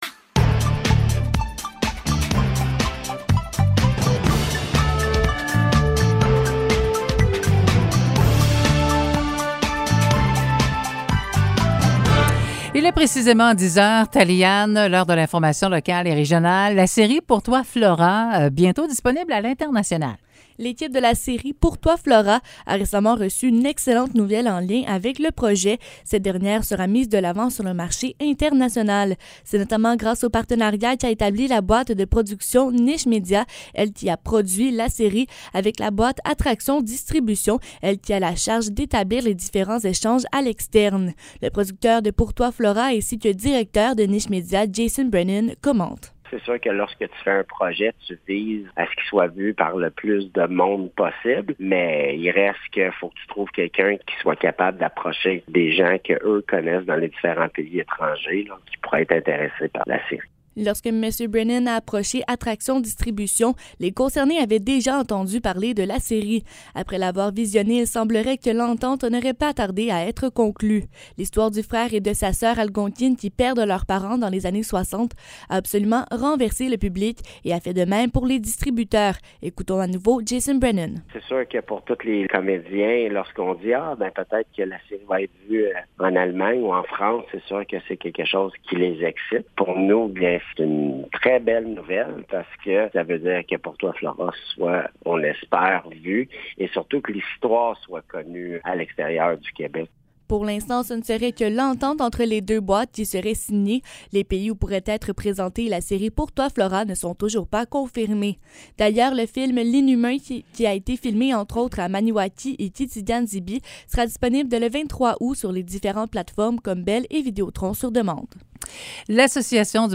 Nouvelles locales - 8 août 2022 - 10 h